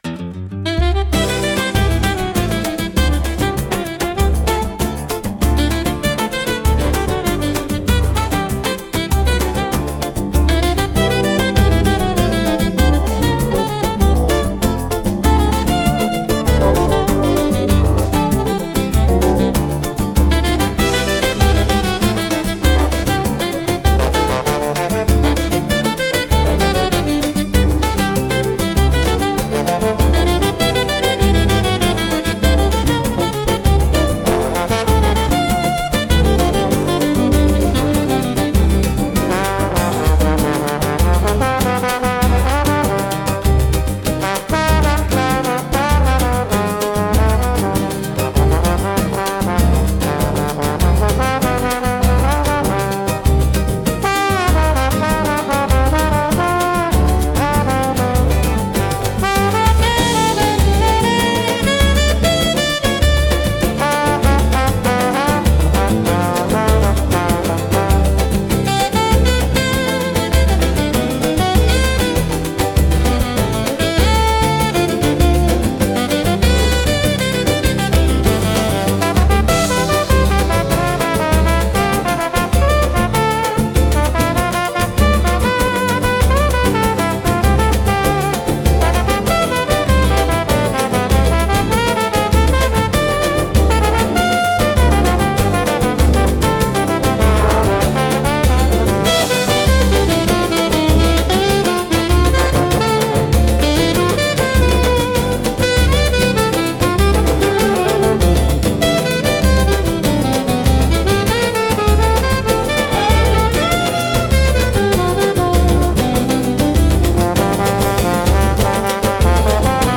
música e arranjo: IA) instrumental 4